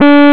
Lead5 d4.wav